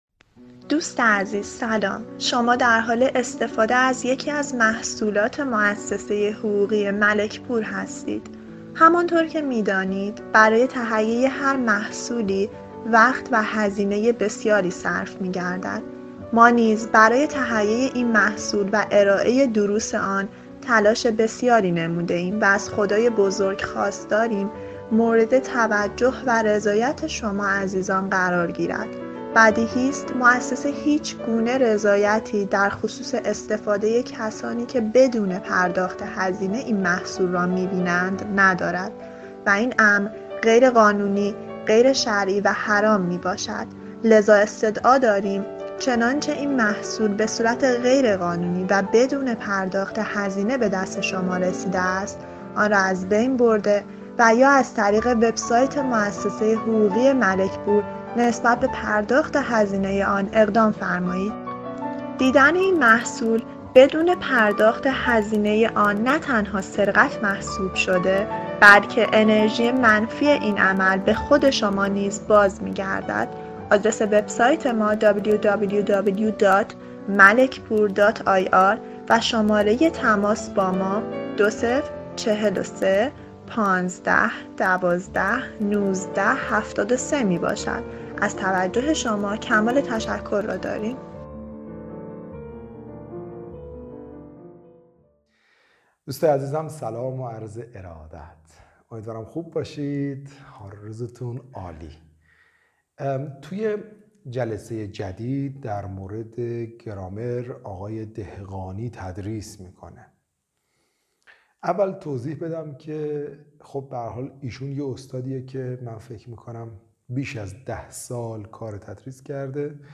درس-سوم-بخش-اول.mp3